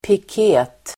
Ladda ner uttalet
piket substantiv, police van, (patrol wagon [US])Uttal: [pik'e:t] Böjningar: piketen, piketerDefinition: ett slags polisbil som används vid utryckning